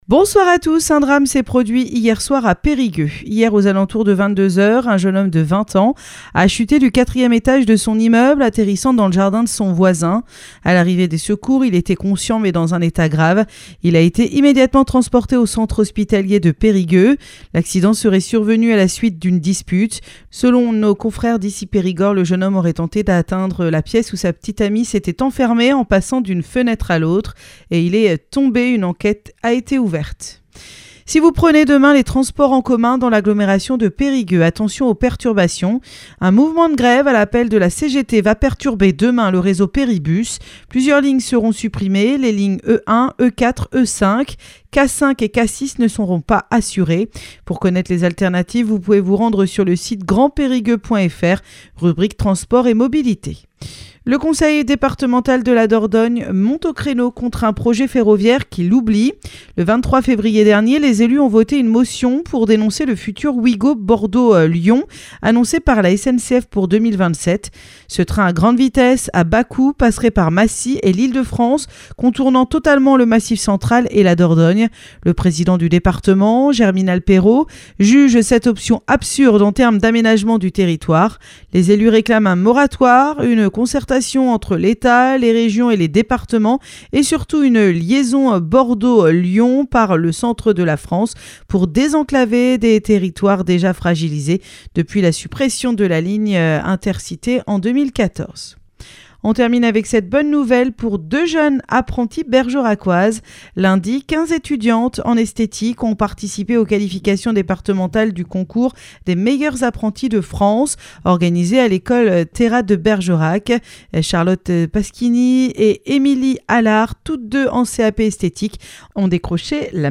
Happy Radio : Réécoutez les flash infos et les différentes rubriques